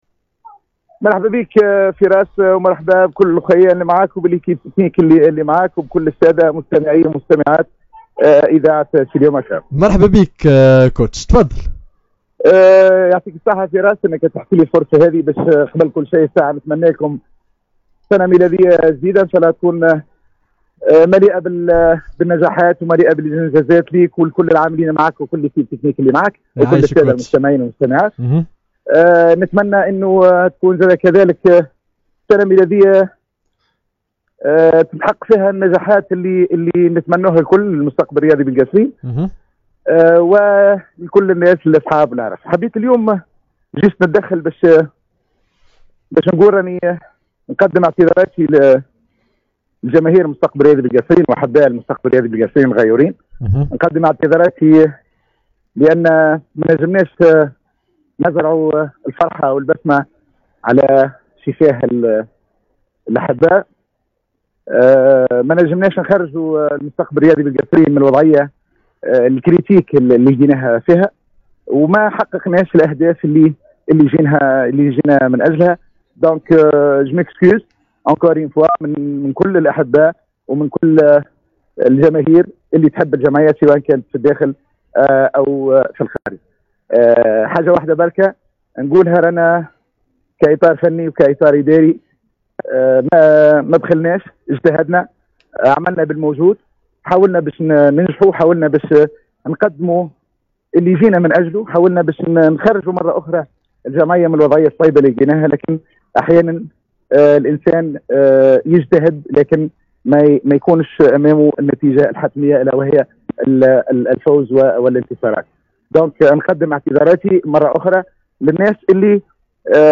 في تصريح حصري للإذاعة سيليوم آف آم